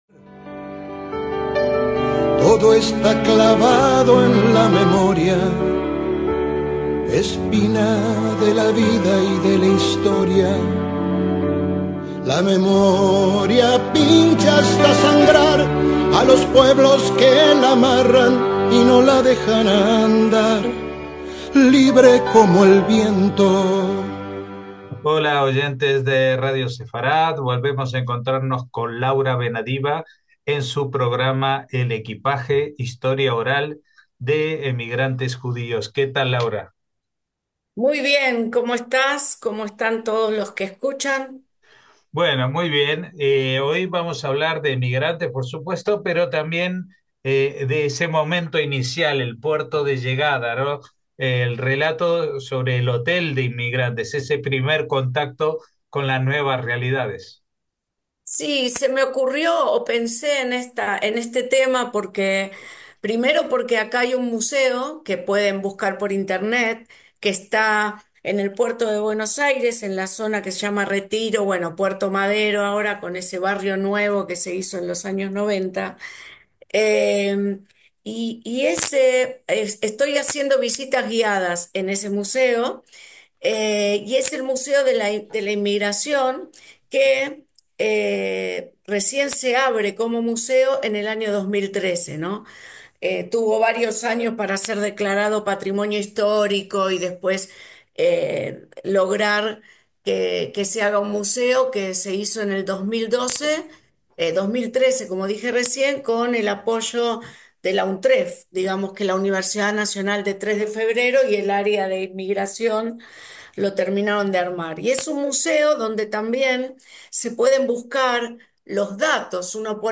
En esta ocasión, los testimonios grabados corresponden al proceso de adaptación de aquellos inmigrantes que llegaban y se hospedaban durante un plazo mínimo (aunque a veces se alargaba) hasta que podían trasladarse a su destino definitivo.